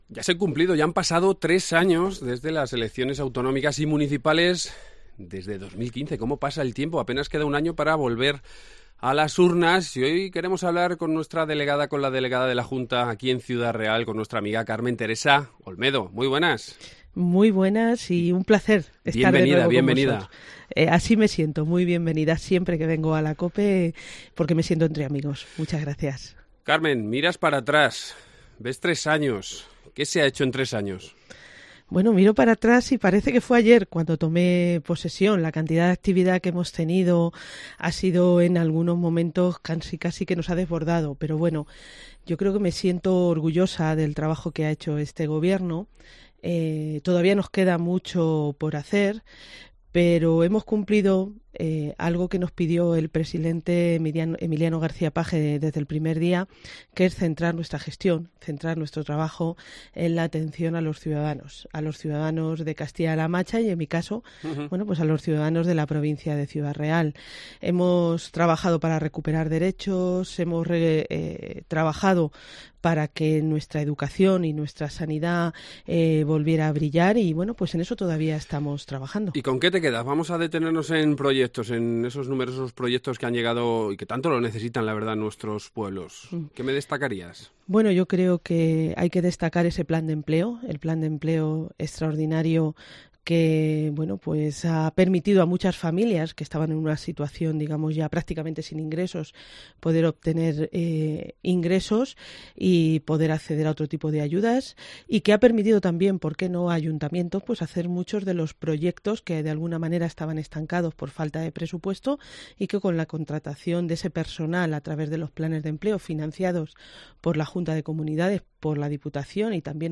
Hoy nos visita aquí en los estudios de Cope la delegada de la Junta, Carmen Teresa Olmedo, y con ella repasamos estos tres años de Gobierno y los proyectos que han llegado, y los que quedan por llegar, a nuestros pueblos.